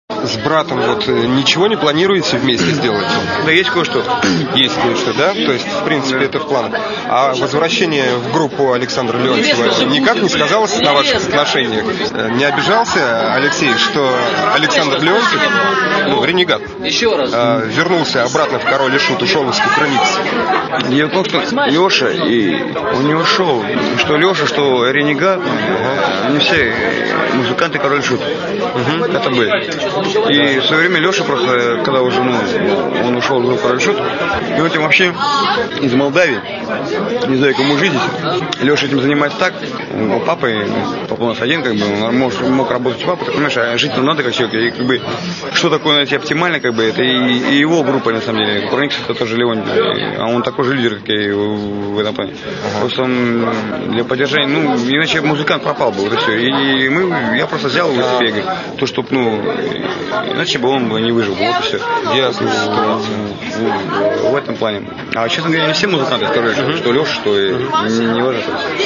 Интервью с Михаилом Горшеневым